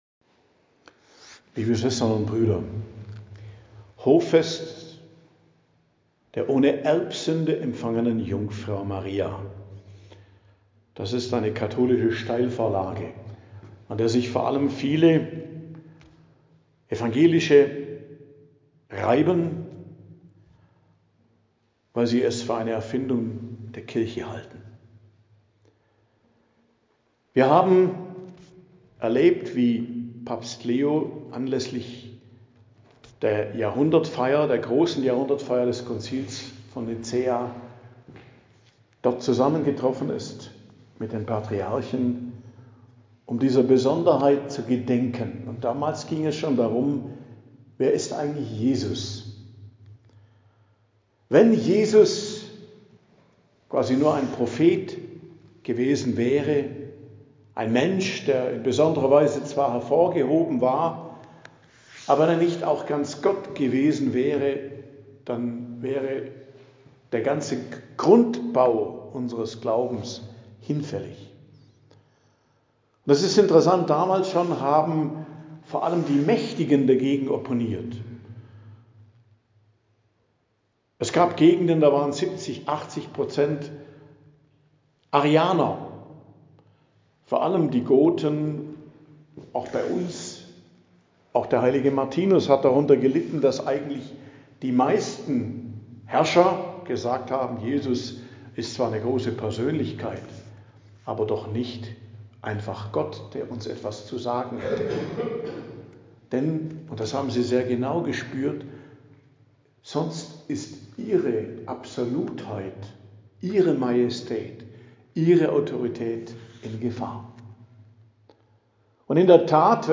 Predigt zum Hochfest der ohne Erbsünde empfangenen Jungfrau und Gottesmutter Maria, 8.12.2025 ~ Geistliches Zentrum Kloster Heiligkreuztal Podcast